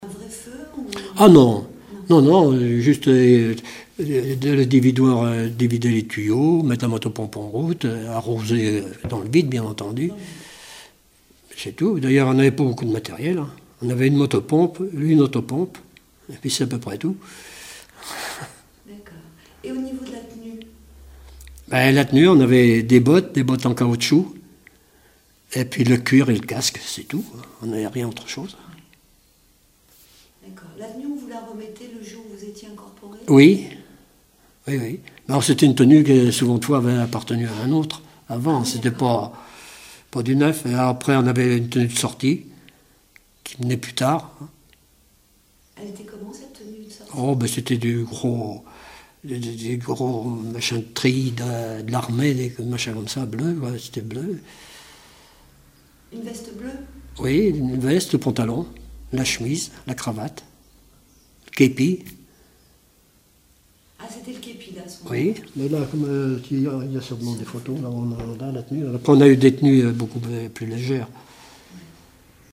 Témoignages d'un ancien sapeur-pompier
Catégorie Témoignage